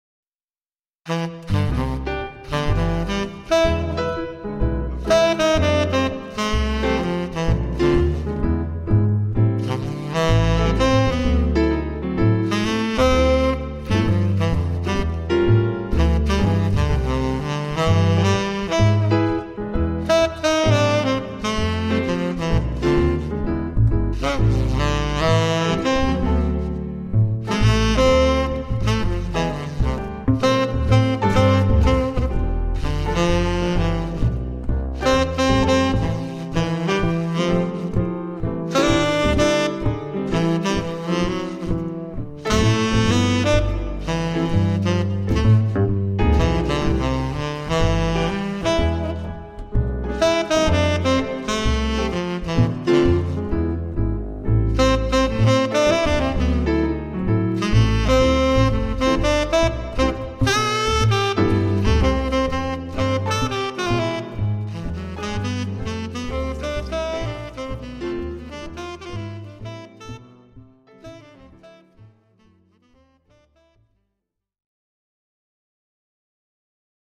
trio